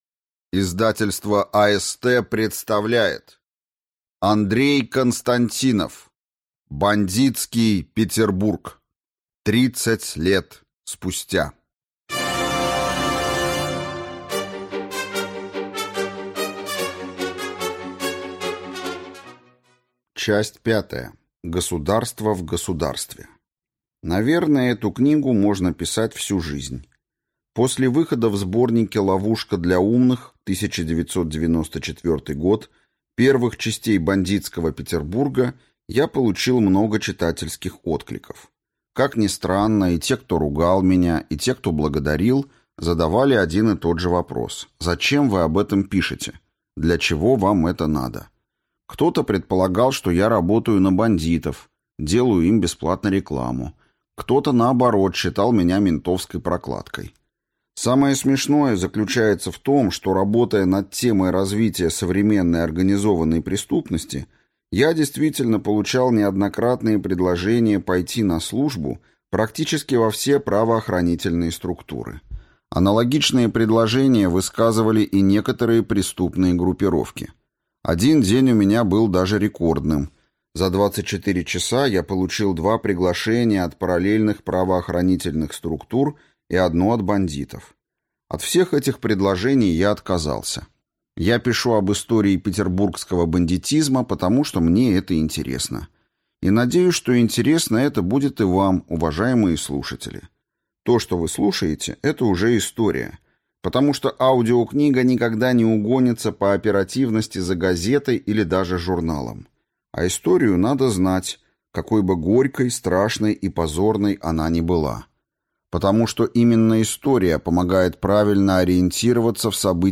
Аудиокнига Бандитский Петербург. Часть пятая. Государство в государстве | Библиотека аудиокниг